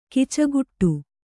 ♪ kacaguṭṭu